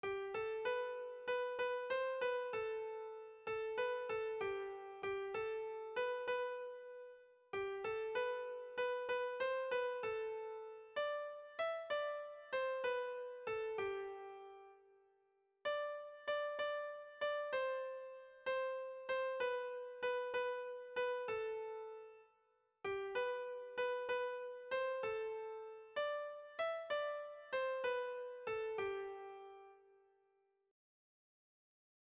Air de bertsos - Voir fiche   Pour savoir plus sur cette section
Sentimenduzkoa
Zortziko ertaina (hg) / Lau puntuko ertaina (ip)
A1A2BA2